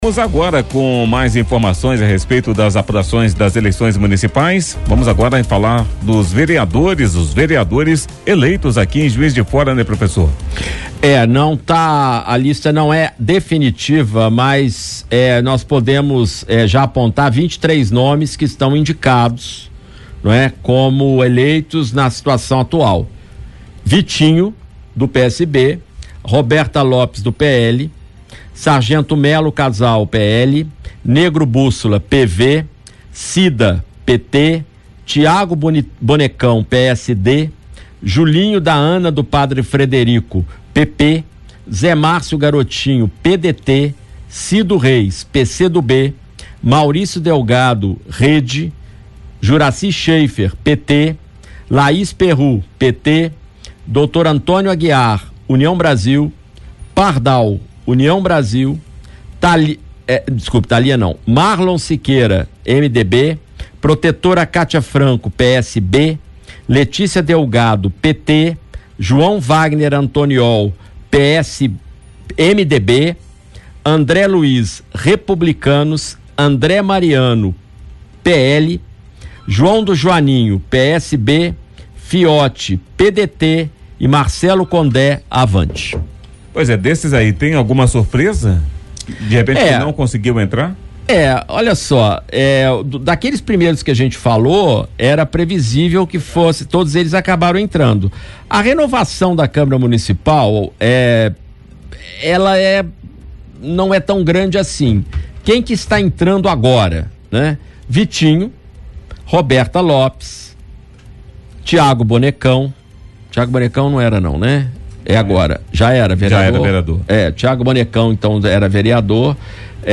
Confira a análise do cientista político